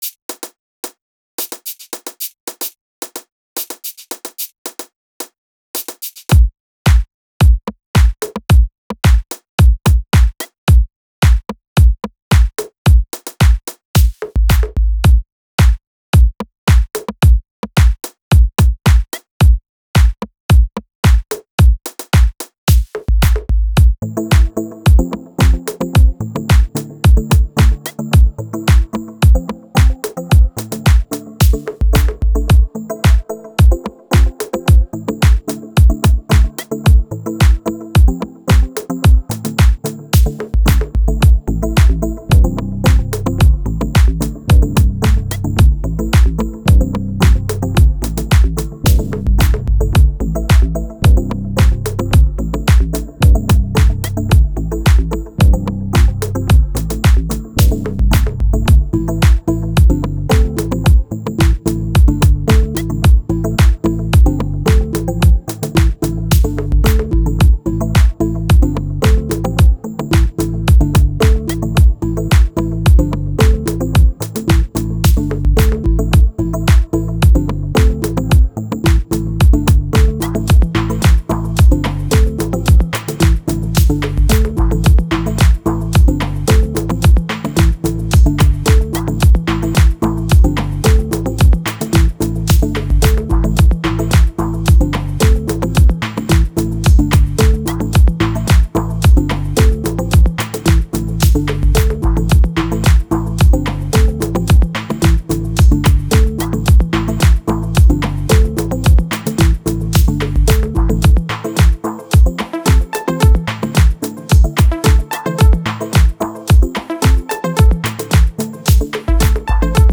10 Minuten lang 100 bpm lizenzfrei